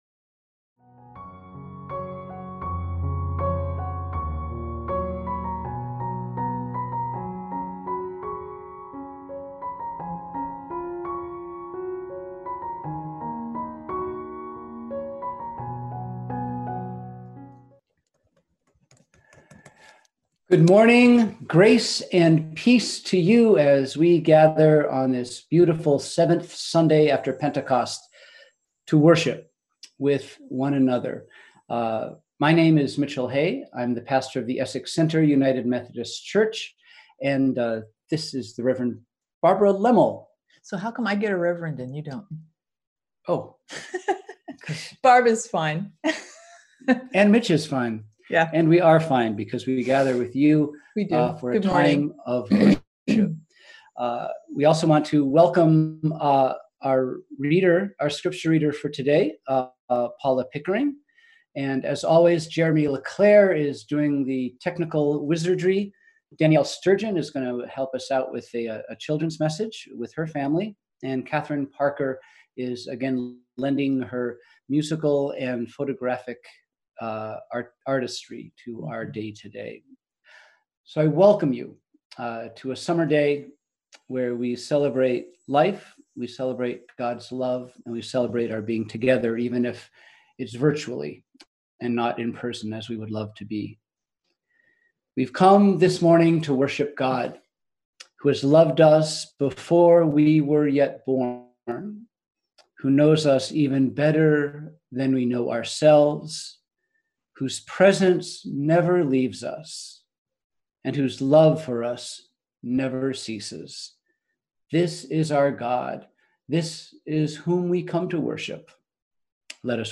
We held virtual worship on Sunday, July 19, 2020!